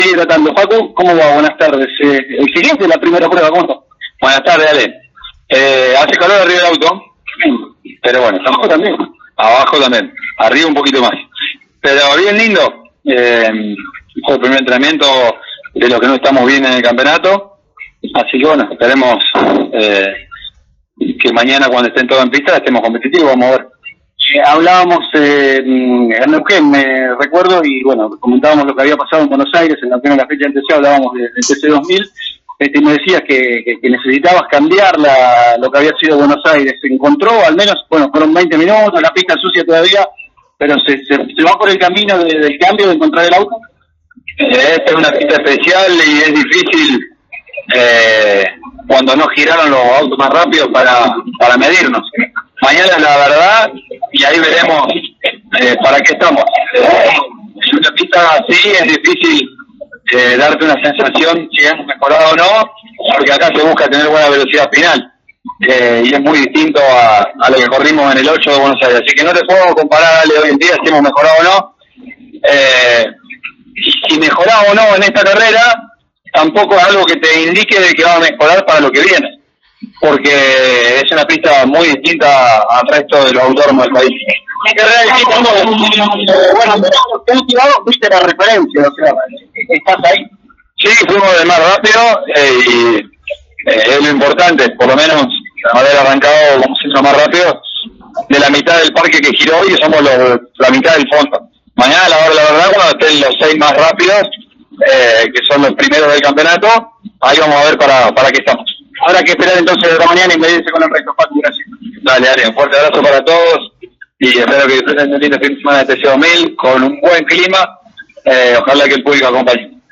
El piloto de Las Parejas fue la referencia en el único entrenamiento que se desarrolló el viernes en Rafaela y habló por nuestros micrófonos, detallando cómo fue el trabajo en pista.